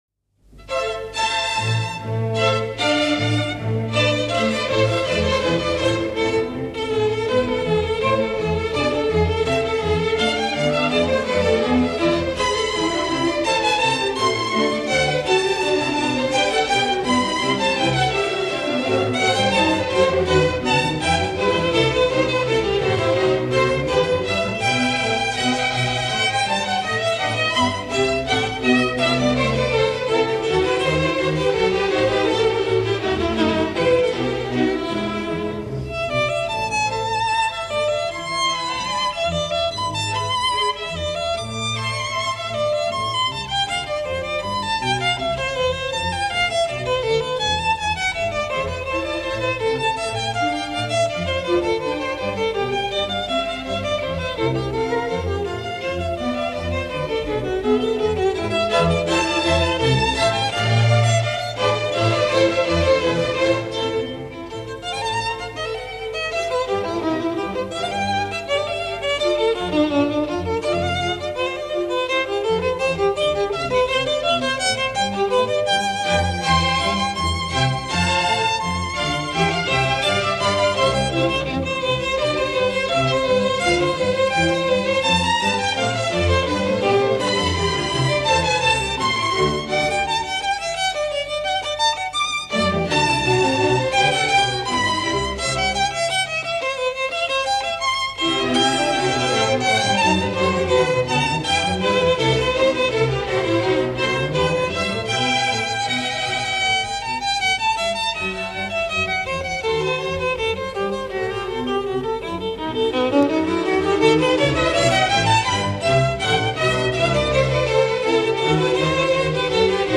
From the Swiss Radio Archives, the legendary Henryk Szeryng in concert with Orchestre de la Suisse Romande under the direction of Ernest Ansermet in a performance of the Bach Violin Concerto BWV 1041, recorded on 10 September 1963 and either broadcast live or delayed.